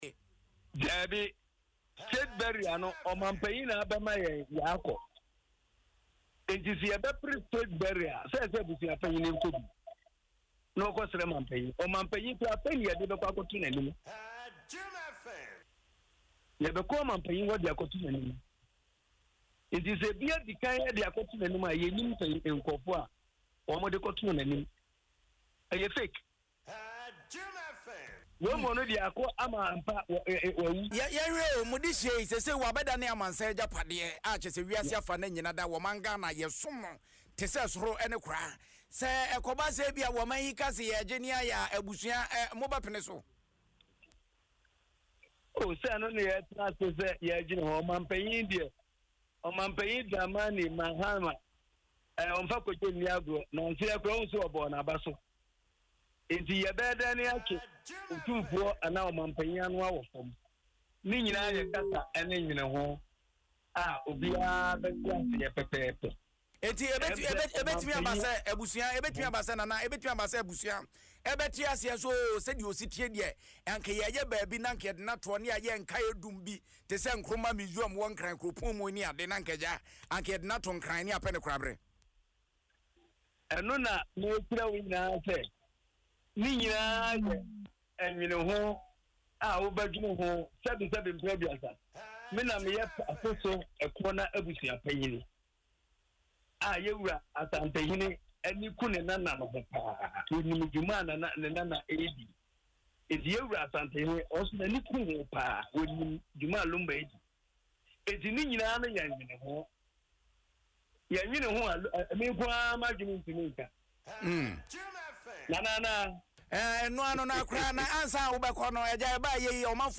Speaking on Adom FM’s Kasiebo is Tasty